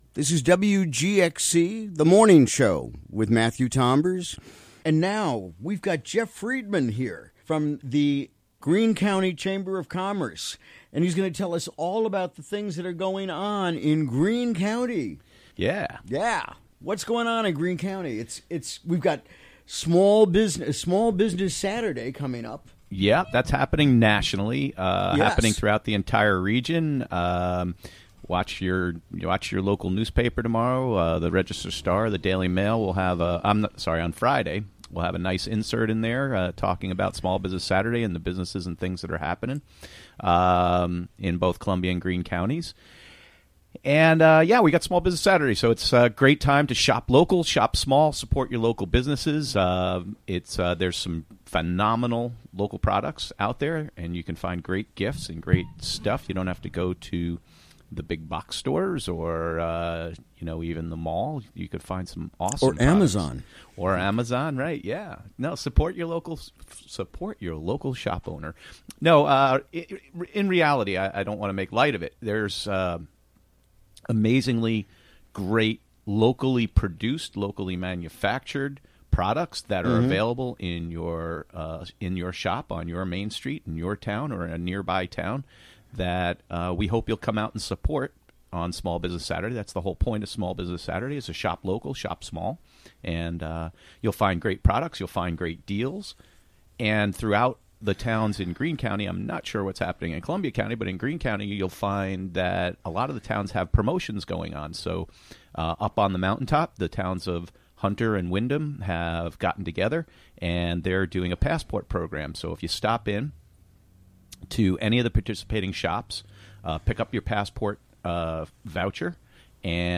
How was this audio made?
Recorded during the WGXC Morning Show of Wednesday, Nov. 22, 2017.